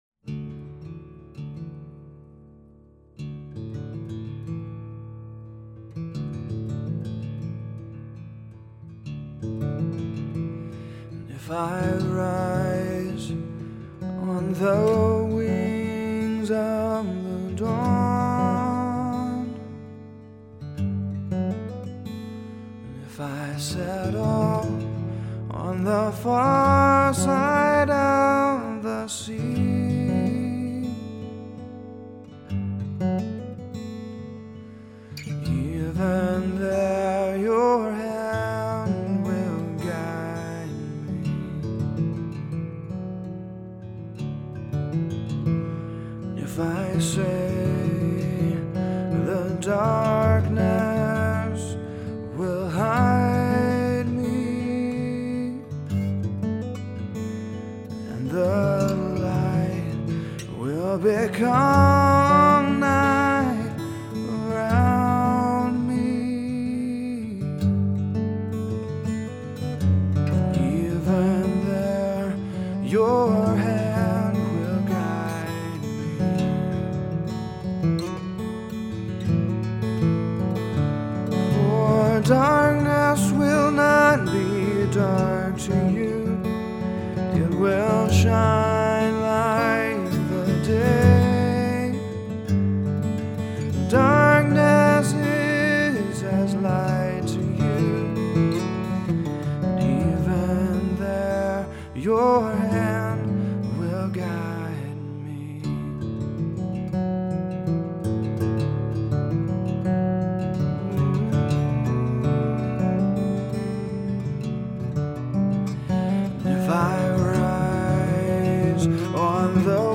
A choral reading taken directly from the NIV. Psalm 139 is written for three voices, but can be arranged for your group.
Tone Serious, contemplative, introspective